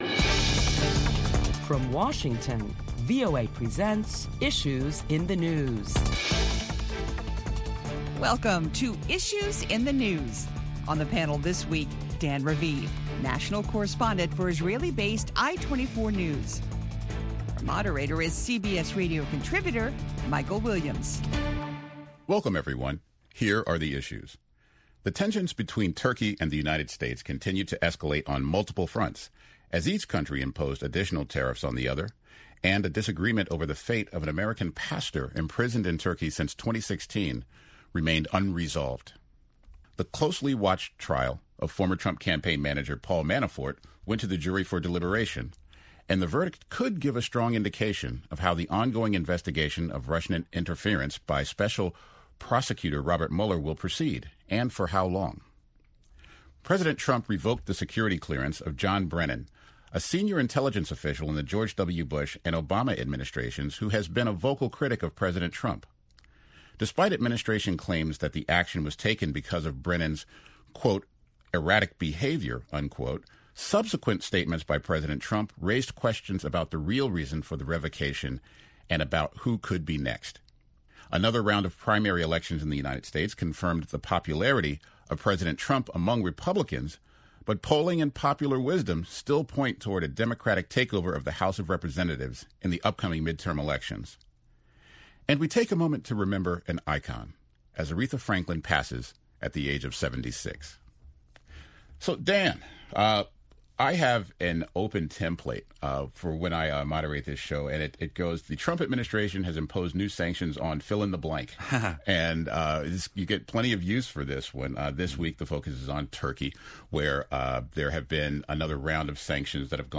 Listen in on a round table discussion about U.S. tariffs in Turkey and the latest in the Paul Manafort trial.